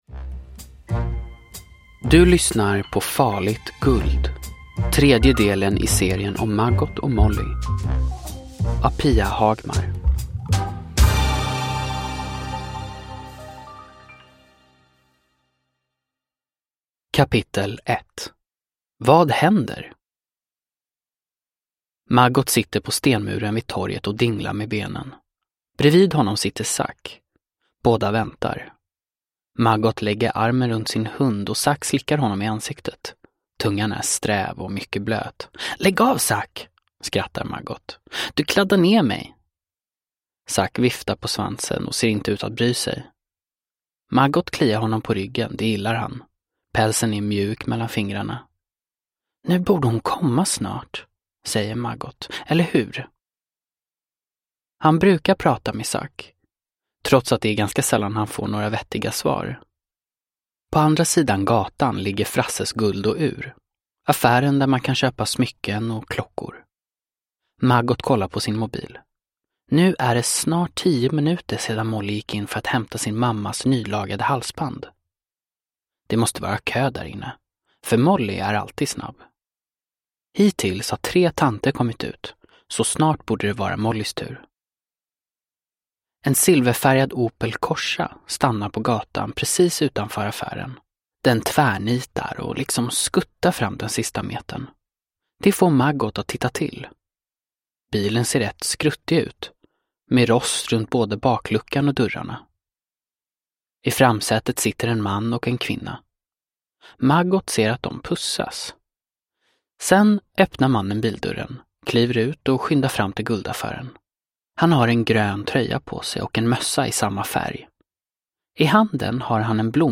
Farligt guld – Ljudbok